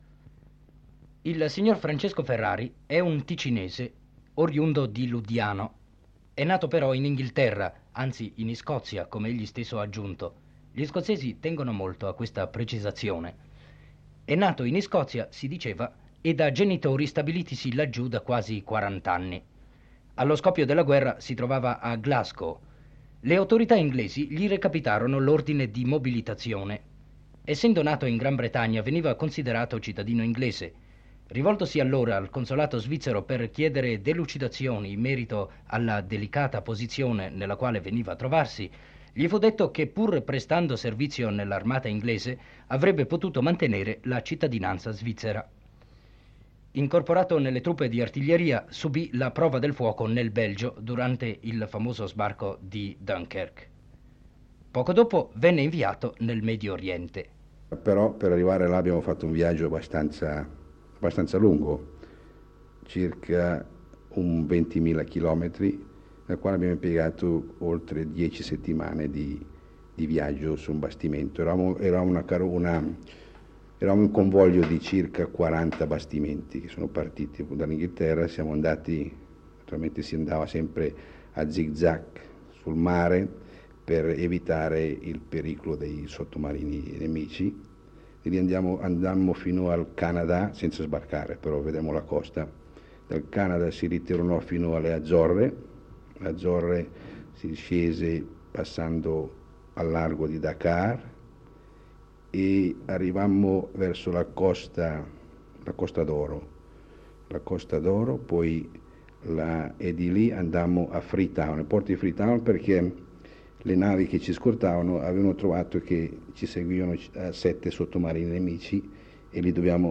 Nell’intervista rievoca vari ricordi legati ai popoli e ai territori incontrati in quel periodo. “Ticinesi raccontano”, 17.12.1955.